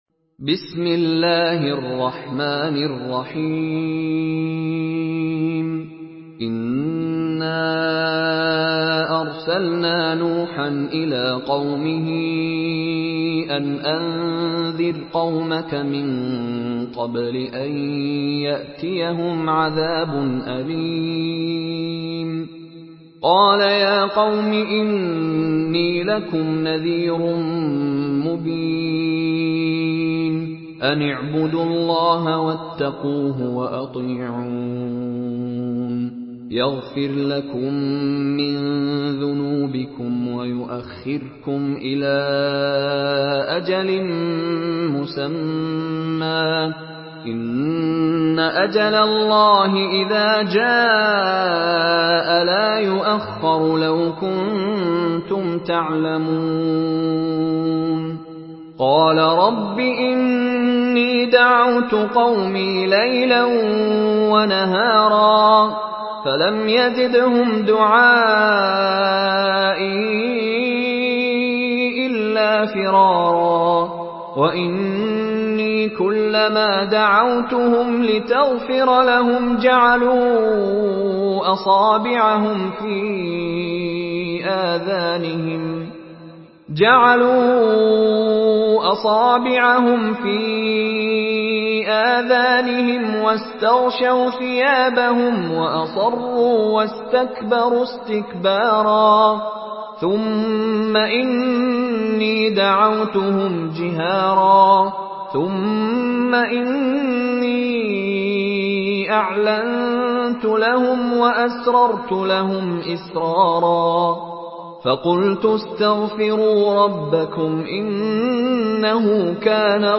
Surah নূহ MP3 by Mishary Rashid Alafasy in Hafs An Asim narration.
Murattal Hafs An Asim